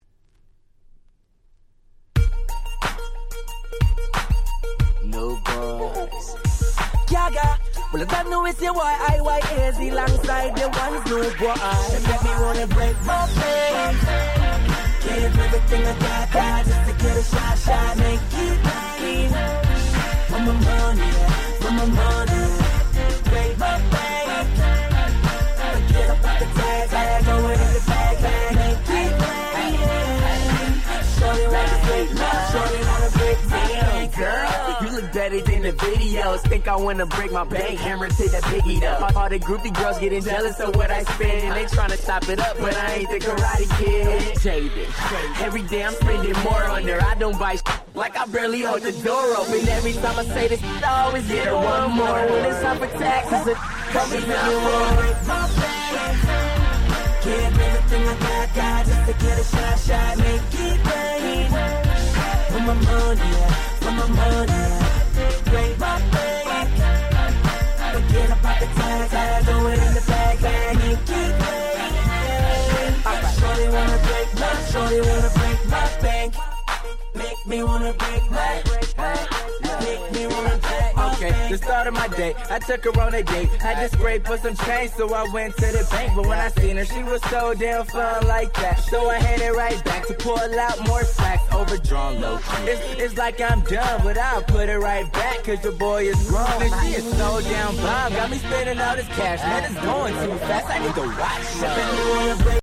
11' Big Hit Hip Hop !!